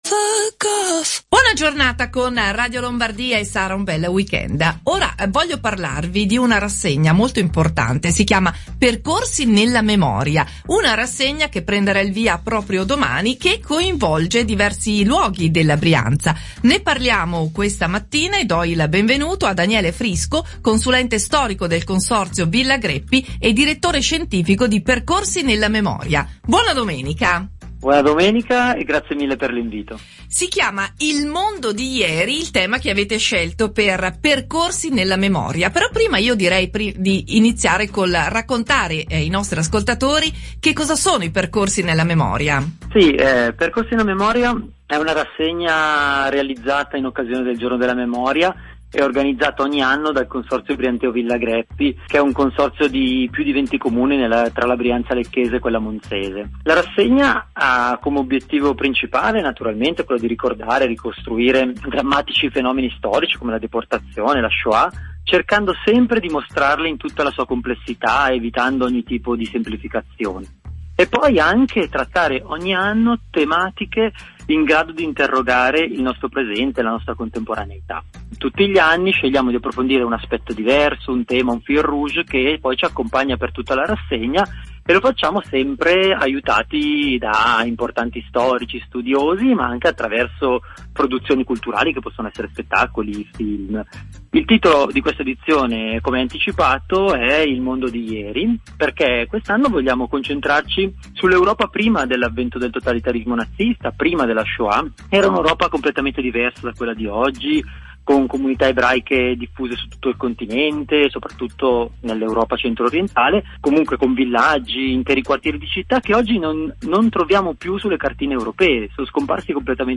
> Intervista di Radio Lombardia